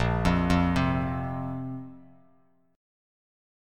A#sus2 chord